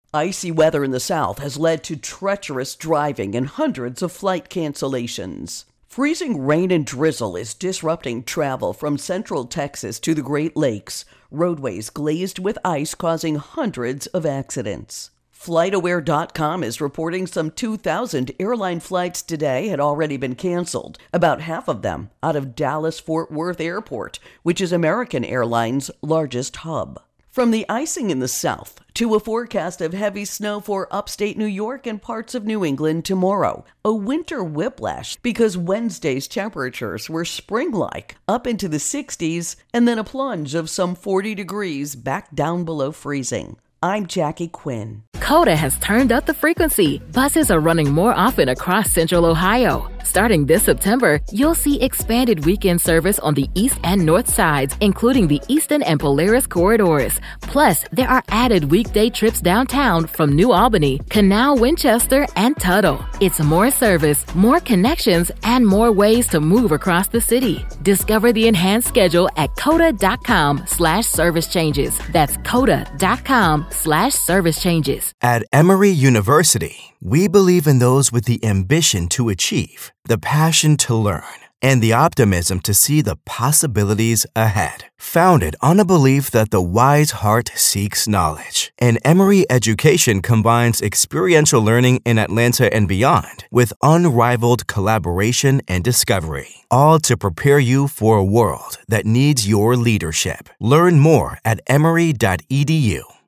Winter Weather Intro and Voicer